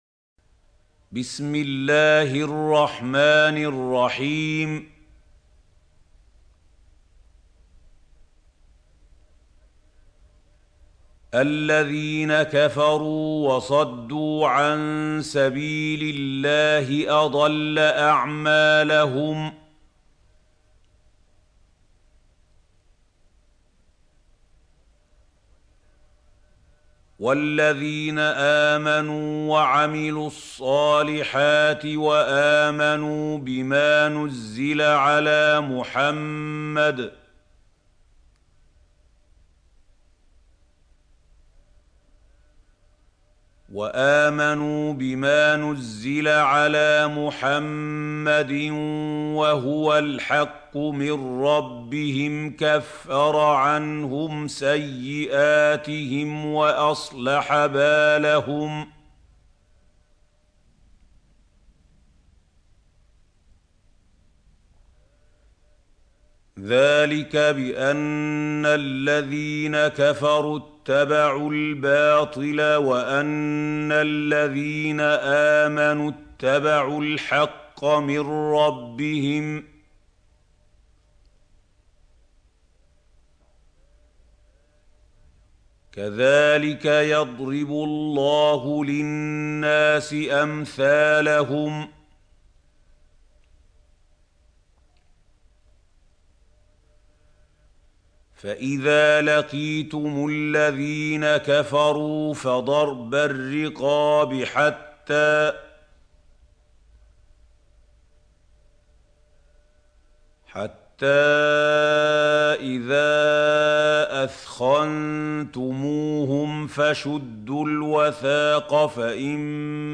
سورة محمد | القارئ محمود خليل الحصري - المصحف المعلم